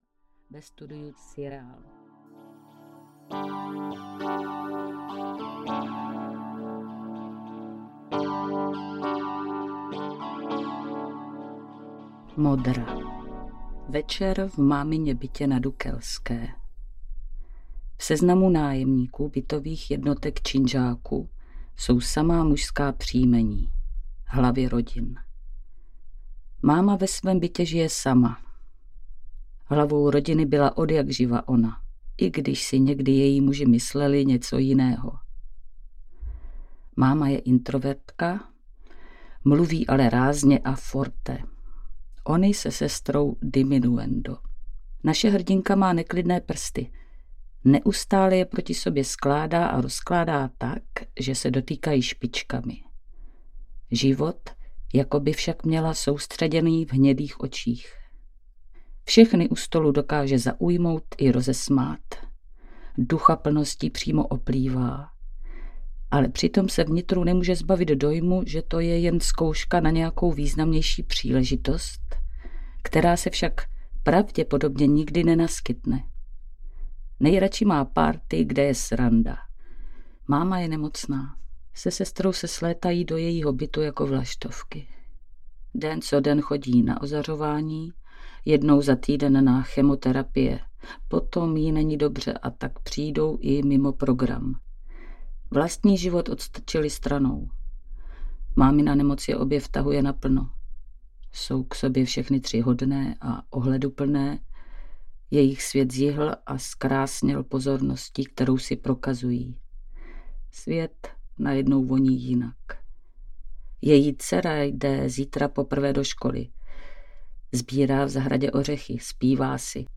Mezerovitý plod audiokniha
Ukázka z knihy